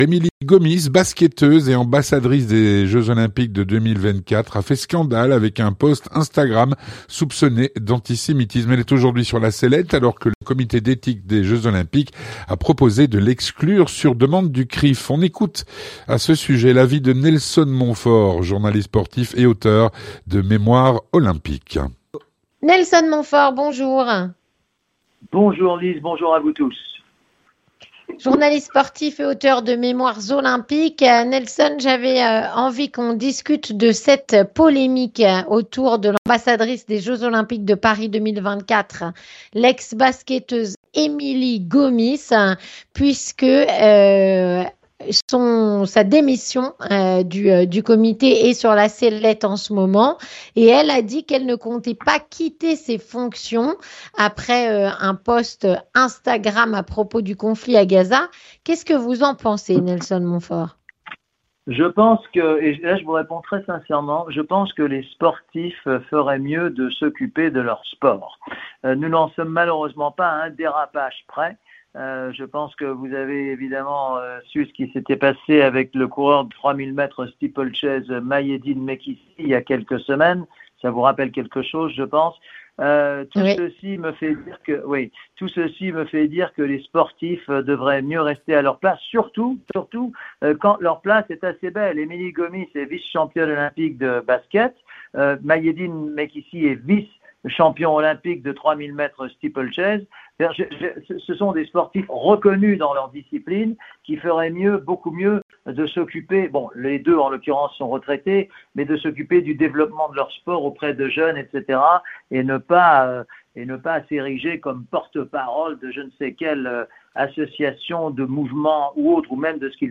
Avec Nelson Montfort, journaliste sportif et auteur de “Mémoires olympiques”.